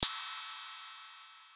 Crashes & Cymbals
PBS - (CYM) Real Trapper.wav